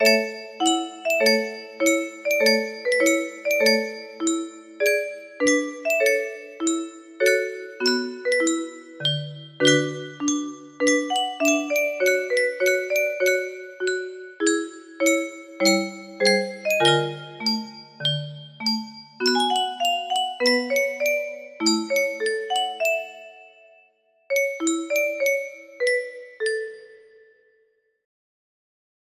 Clone of toreador song 30 01 music box melody
Yay! It looks like this melody can be played offline on a 30 note paper strip music box!